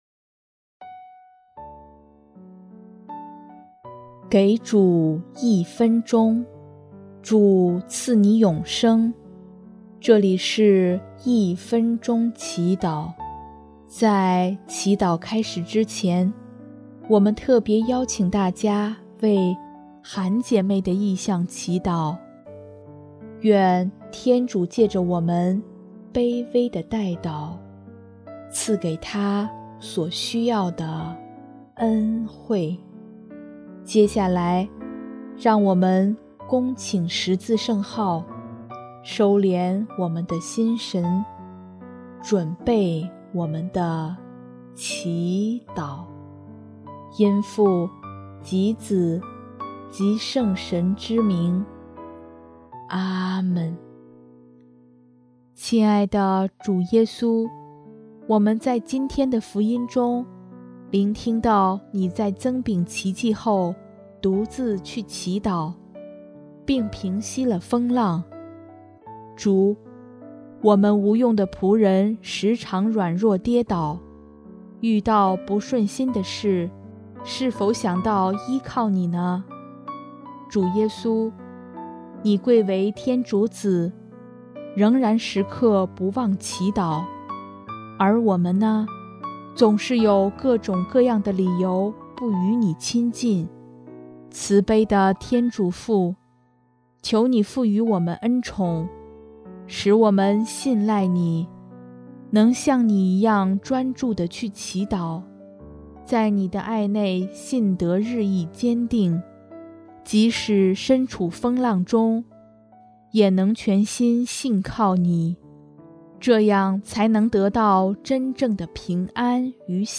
【一分钟祈祷】|1月7日 信赖主得平安